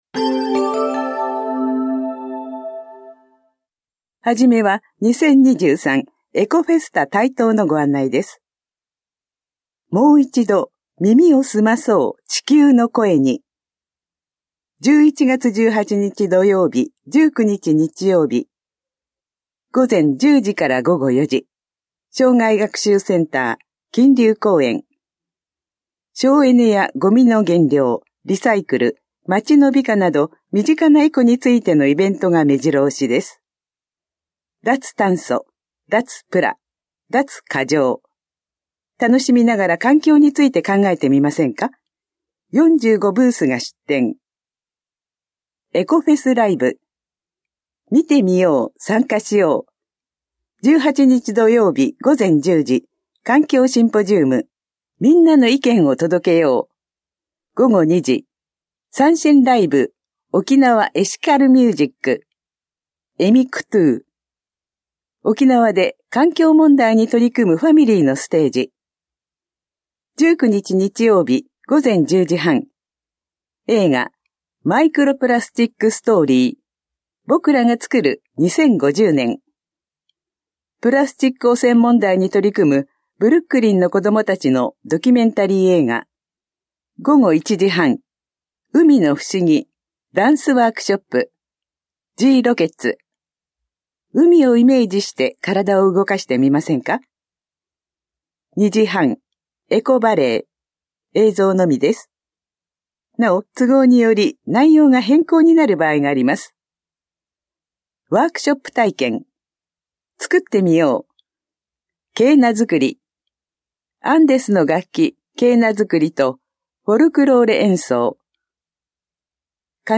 広報「たいとう」令和5年10月20日号の音声読み上げデータです。